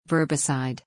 PRONUNCIATION:
(VUHR-buh-syd)